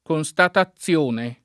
k0nStato] — meno com. costatare: costato [koSt#to o k0Stato] — uguale alternanza nel der. constatazione [